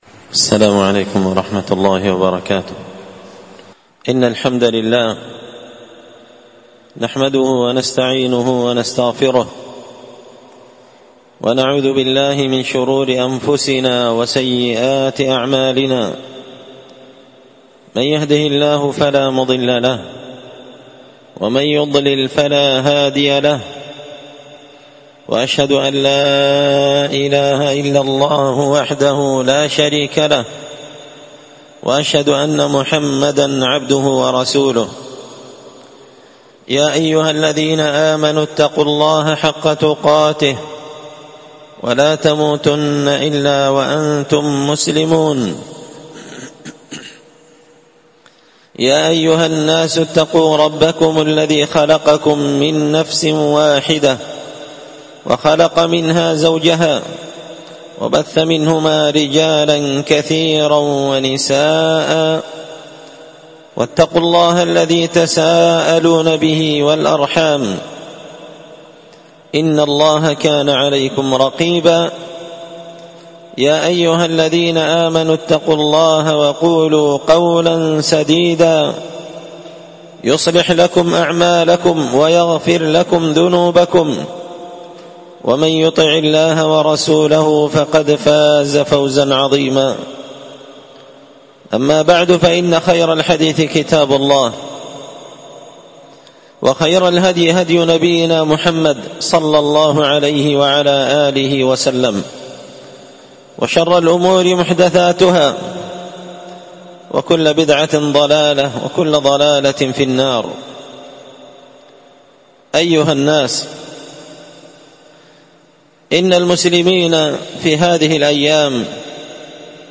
خطبة جمعة بعنوان – التبيان لخصائص رمضان